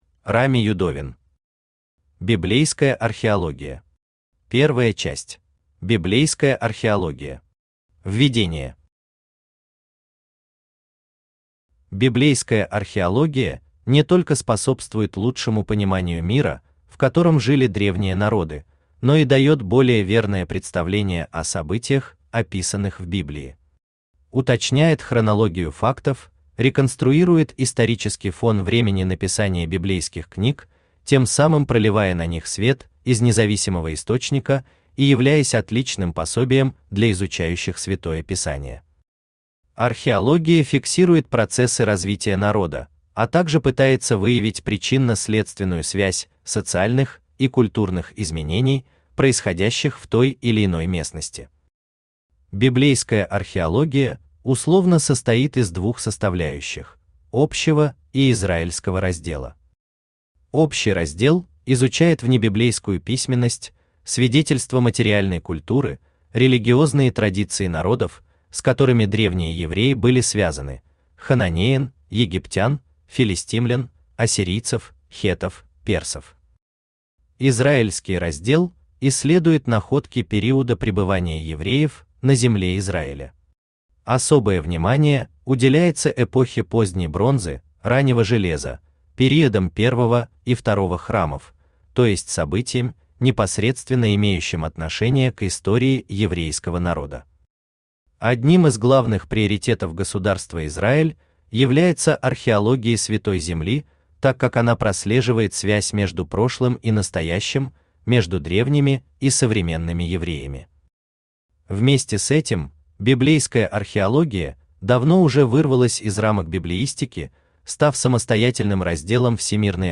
Аудиокнига Библейская археология. Первая часть | Библиотека аудиокниг
Первая часть Автор Рами Юдовин Читает аудиокнигу Авточтец ЛитРес.